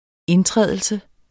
Udtale [ -ˌtʁεˀðəlsə ]